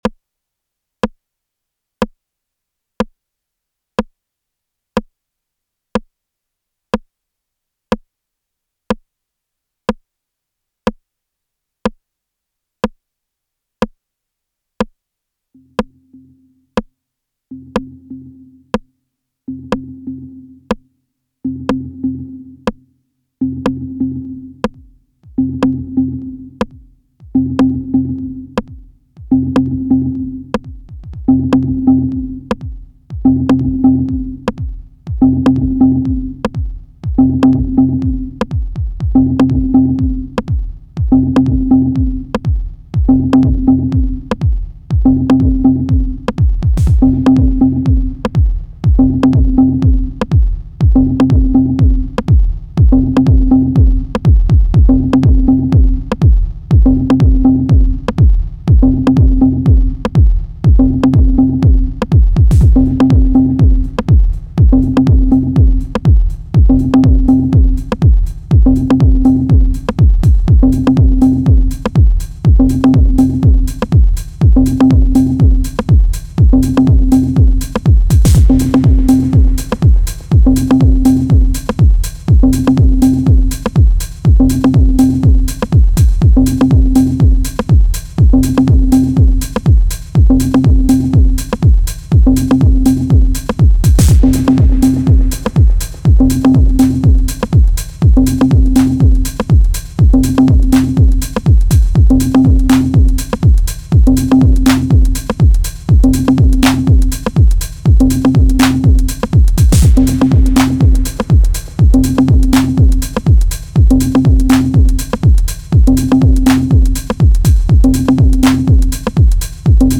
Roland TR-1000 Rhythm Composer
Here’s a wet yet analog 909 kit I put together as a personal starting point, to itch the Farley Houseapella / Reese & Santonio scratch: